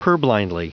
Prononciation du mot purblindly en anglais (fichier audio)
purblindly.wav